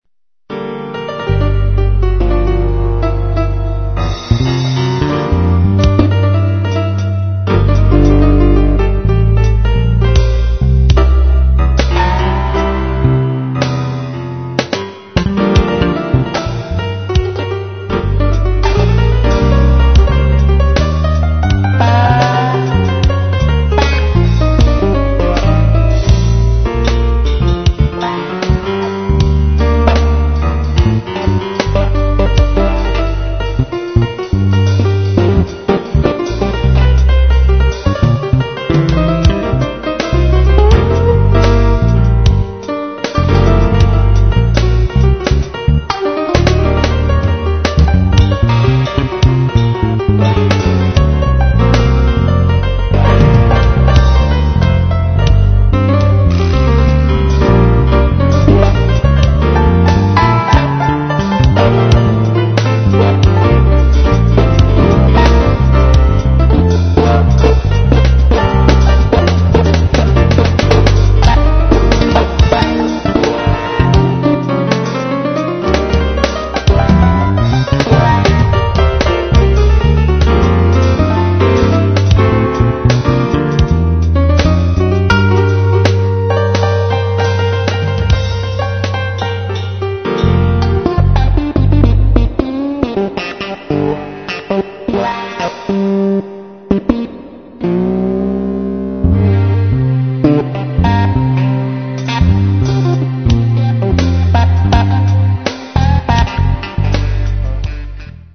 jazz piece